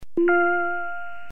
Ding